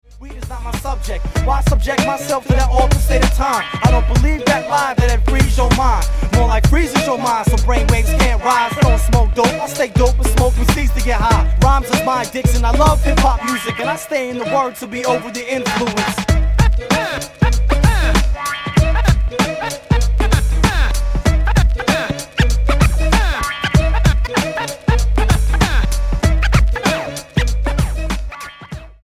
STYLE: Hip-Hop